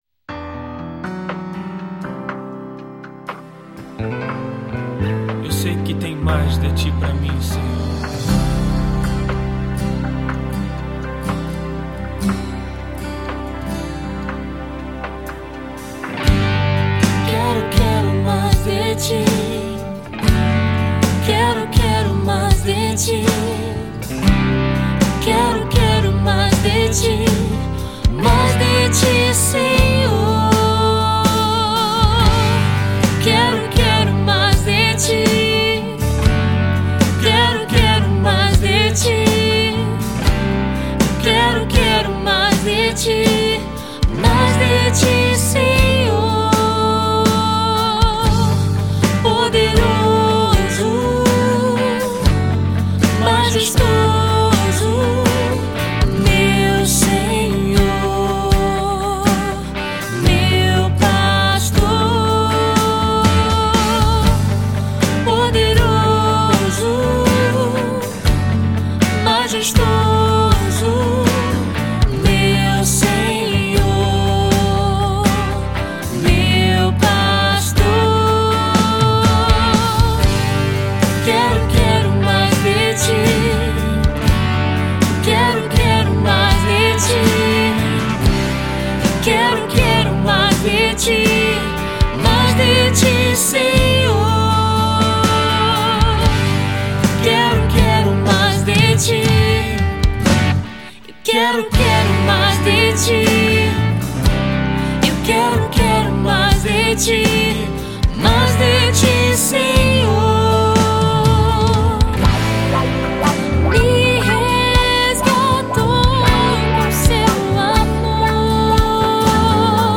com canções de louvor e adoração em estilo pop rock.